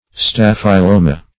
staphyloma.mp3